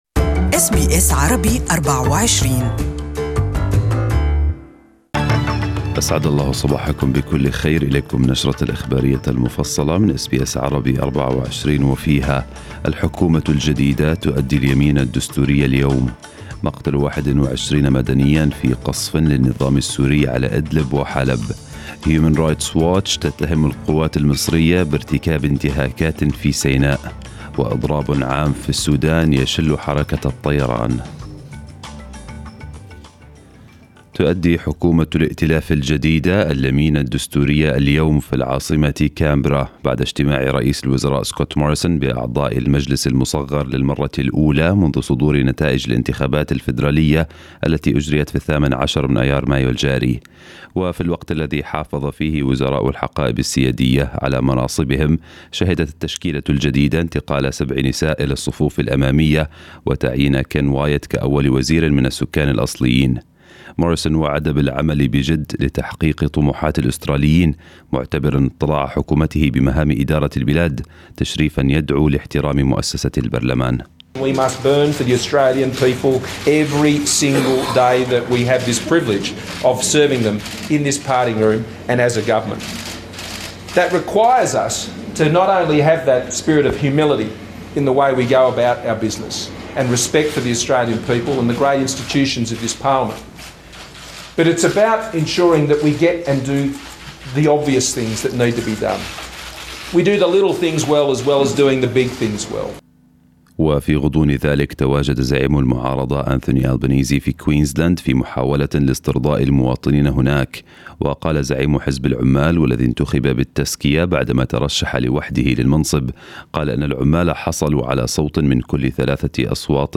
Morning news bulletin in Arabic 29/5/2019